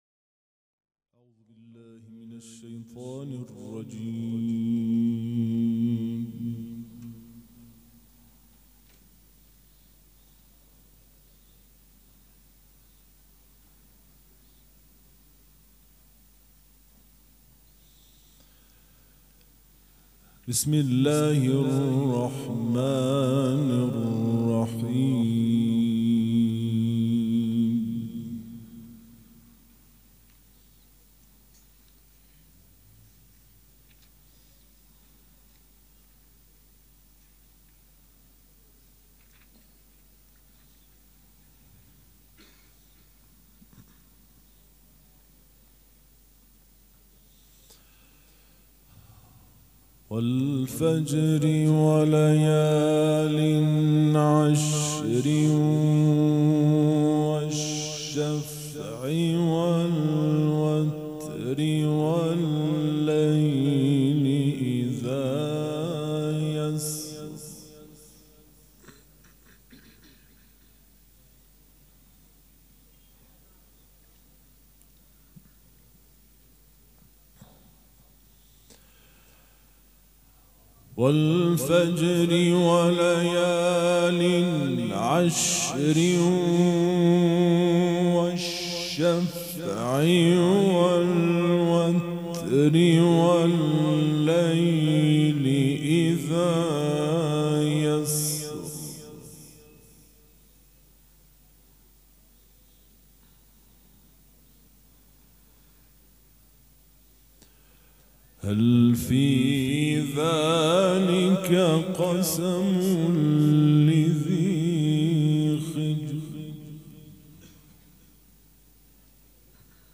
قرائت قرآن
مراسم عزاداری شب پنجم